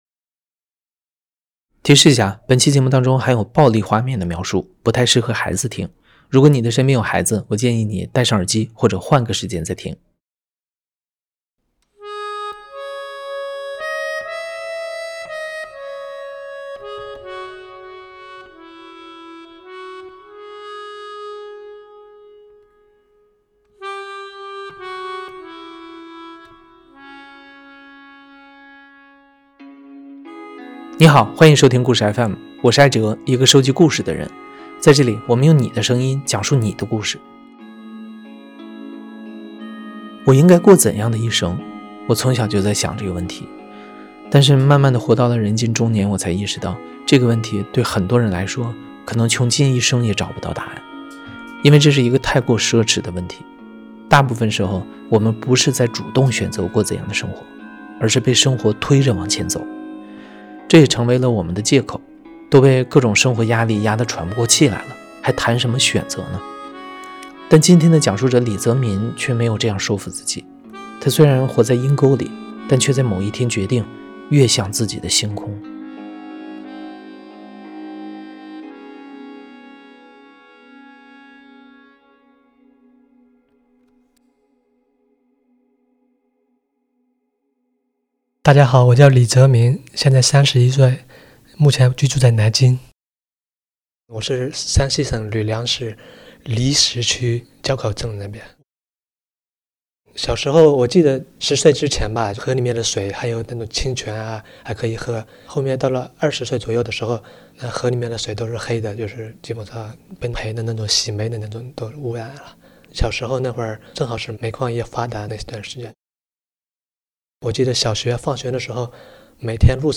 故事FM 是一档亲历者自述的声音节目。